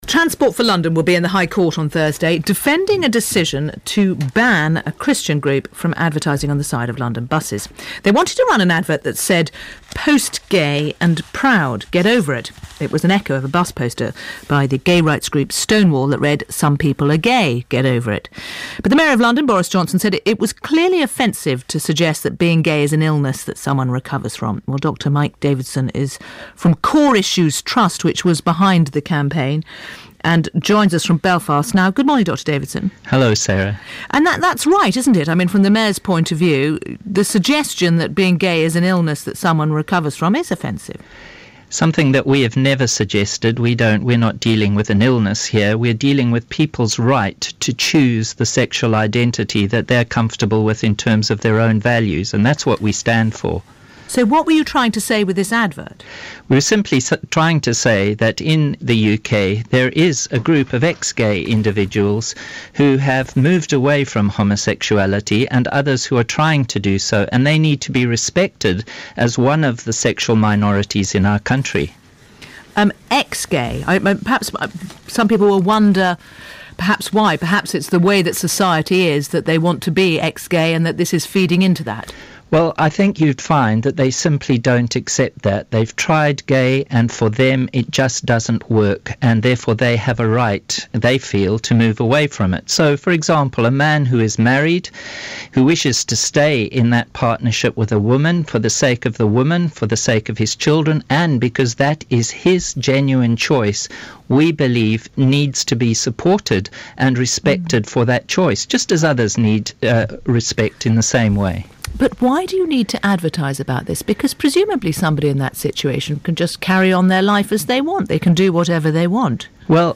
Therapeutic help for unwanted same-sex attraction a BBC 4 report - 12th March 2015